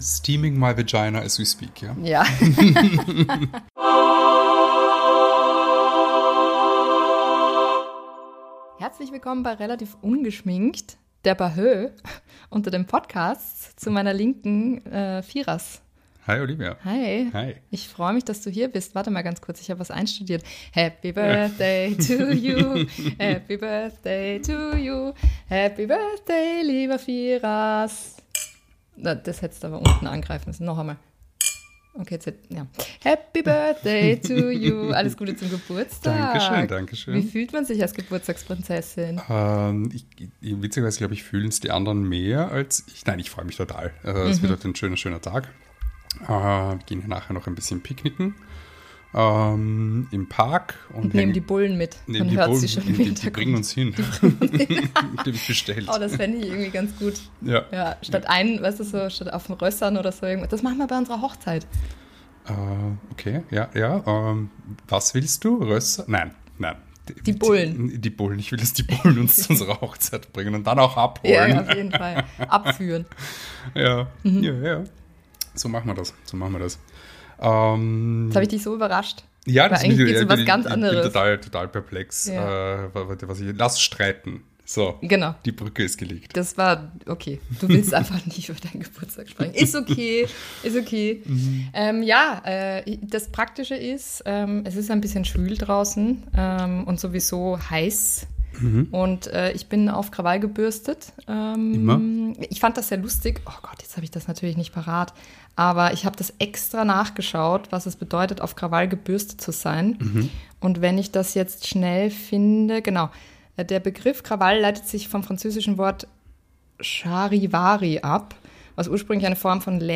Intro: Sound Effect